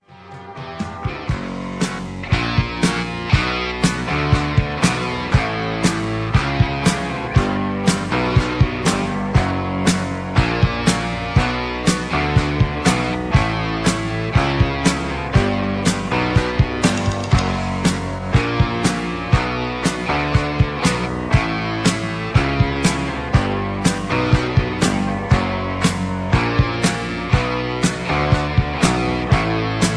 (Key-Bm) Karaoke MP3 Backing Tracks
Just Plain & Simply "GREAT MUSIC" (No Lyrics).